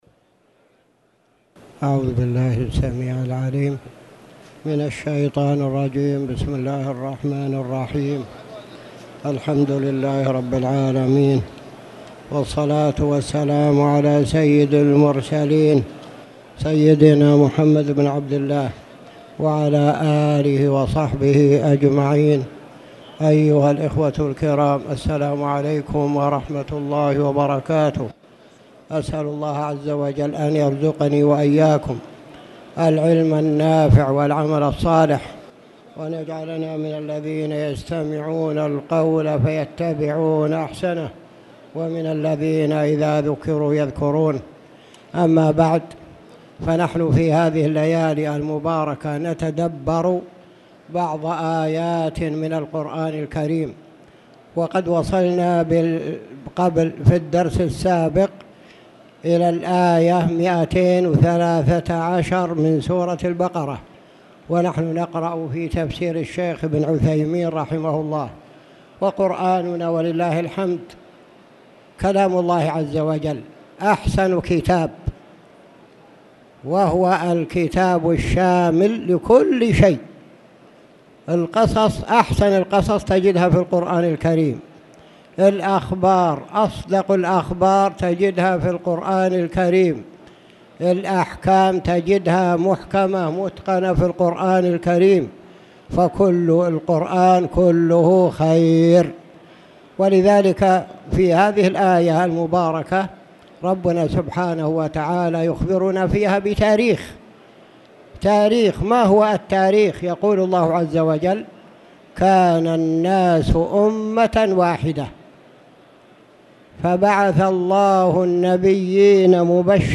تاريخ النشر ٦ رمضان ١٤٣٧ هـ المكان: المسجد الحرام الشيخ